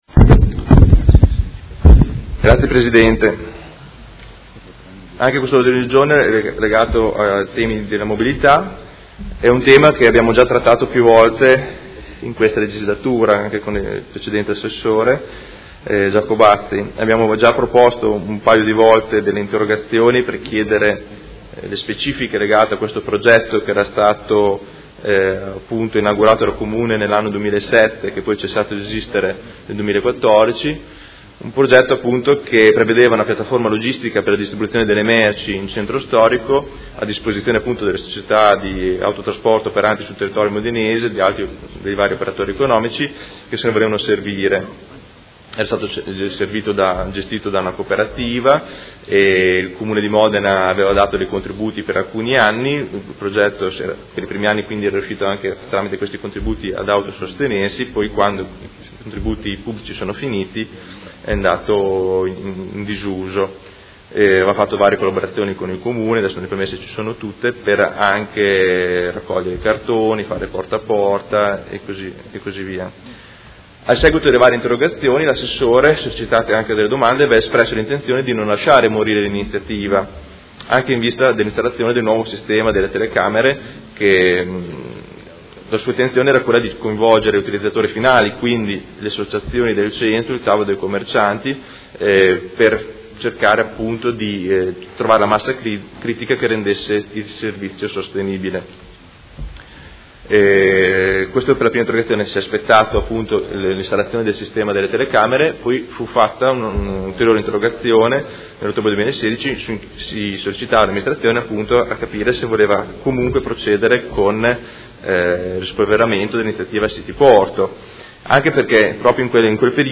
Seduta del 20/12/2018. Presenta mozione Prot. Gen. 207657
Audio Consiglio Comunale